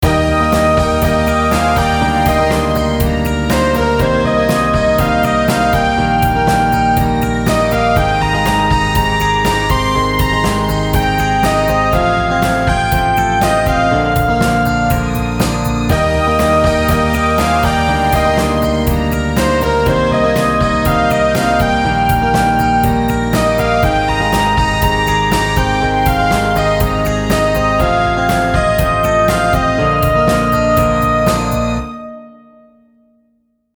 各トラックの音量設定がおかしかったみたいで、ドラムが埋もれすぎてたのを修正。
コーラストラックもリードより音量大きくてリードを聴けなかったりしたしね。